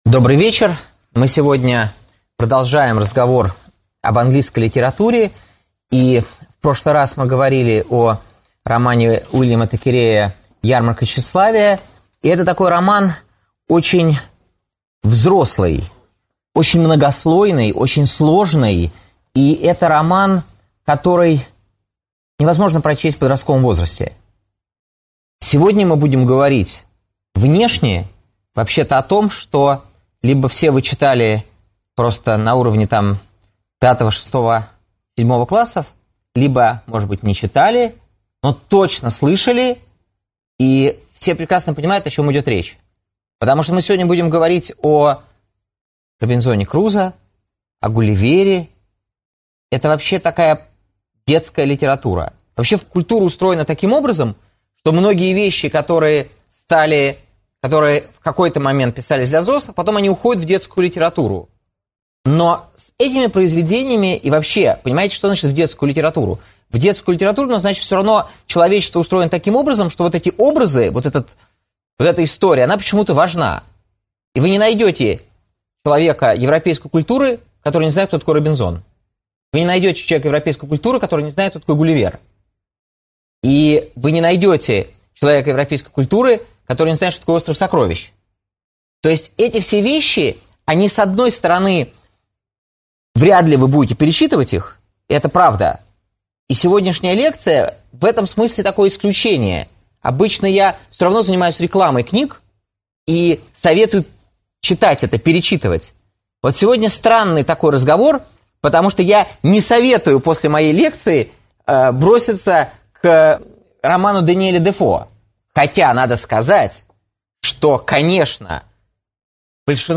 Аудиокнига Даниель Дефо, Джонотан Свифт, Уильям Голдинг. Возможность острова | Библиотека аудиокниг